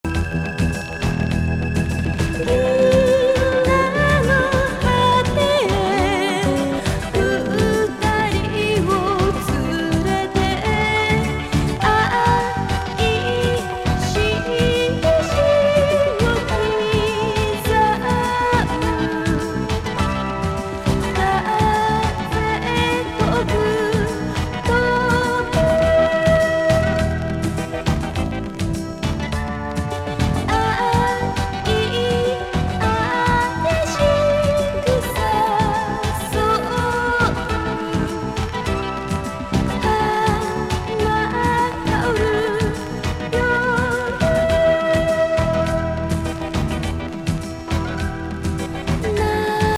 メロウ
エスノ歌謡・テクノポップ